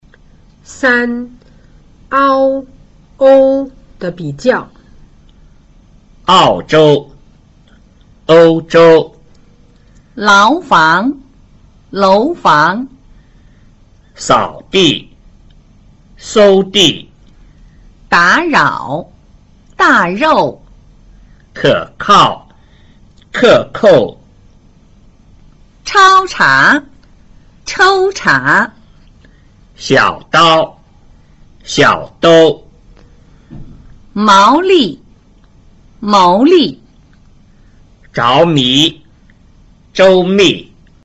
ao   口腔開度由開逐漸變為半閉或閉，唇形由不圓變為圓。
ou    口腔開度由半閉逐漸變為閉唇形由不大圓變為圓。
3 ao – ou的比較